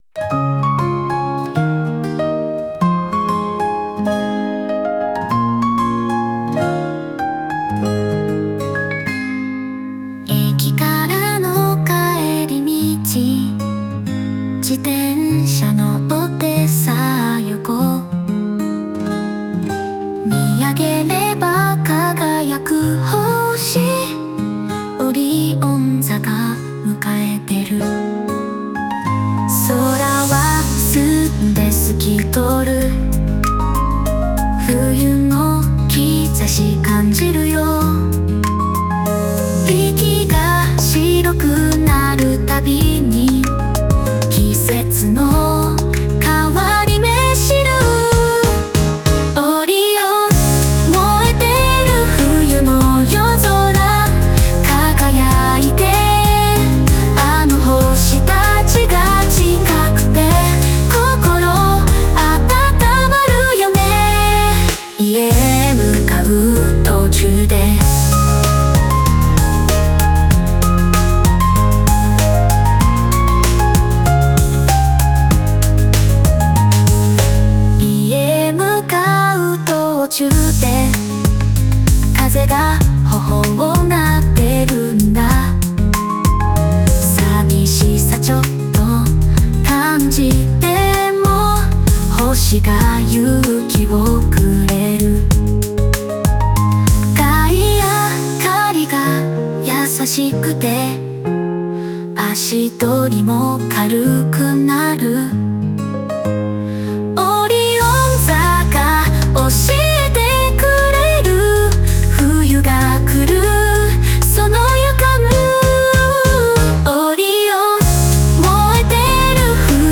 その透き通るような美しい声と、情感豊かな表現力で知られる日本のボーカリストです。